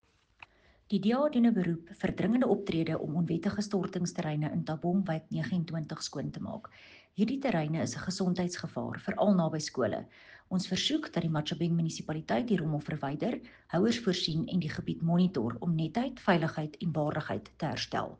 Afrikaans soundbite by Cllr René Steyn and